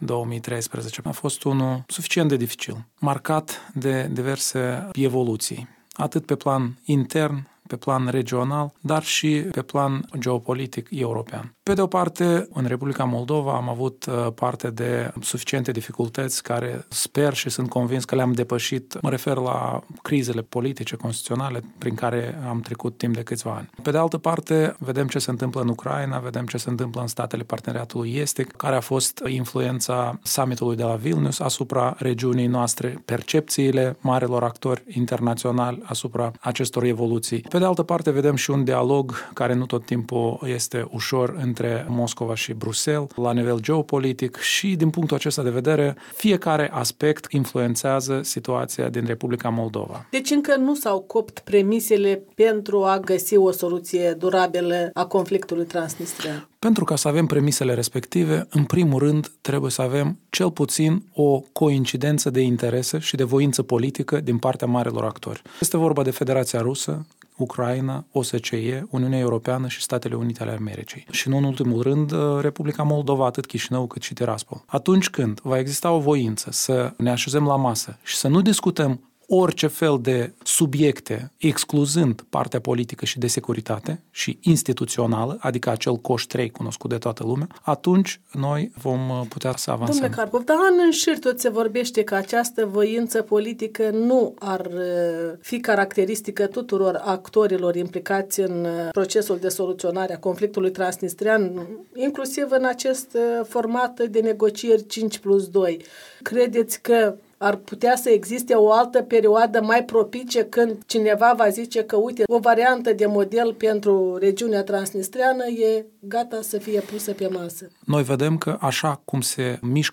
Un interviu cu vicepremierul Eugen Carpov